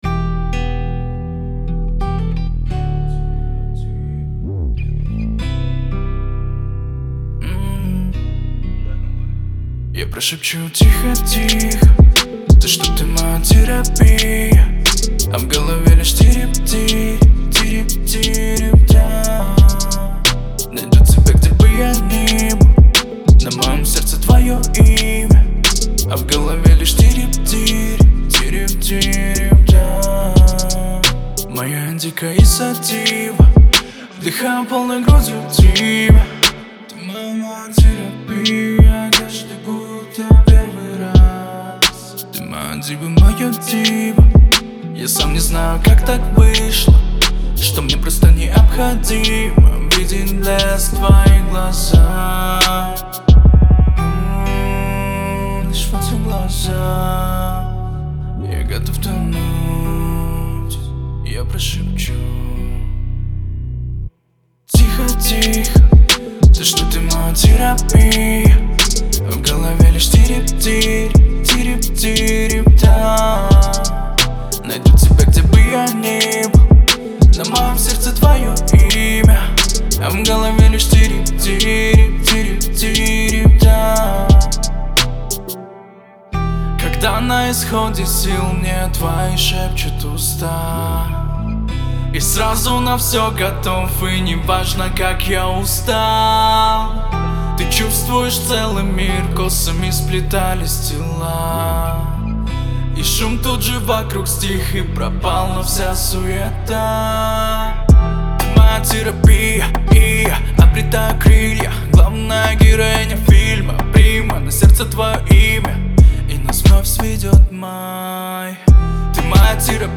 это атмосферная песня в жанре поп с элементами R&B